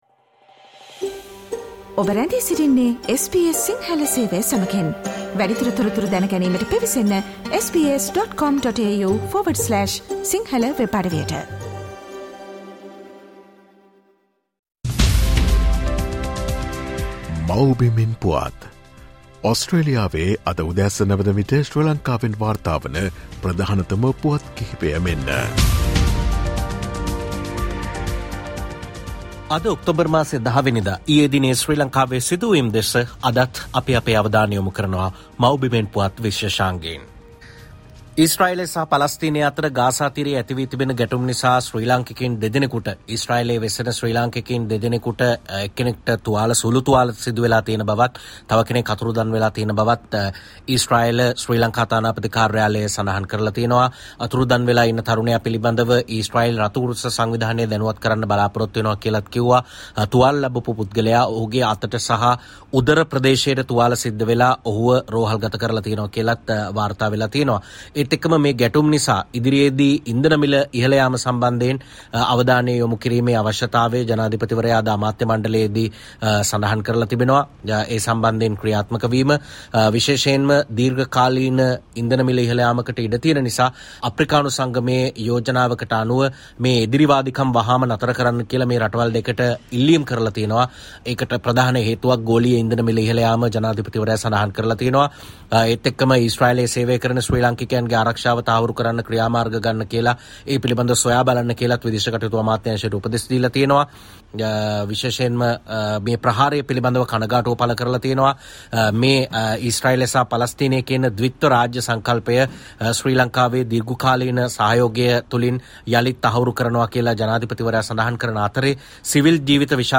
SBS Sinhala featuring the latest news reported from Sri Lanka - Mawbimen Puwath.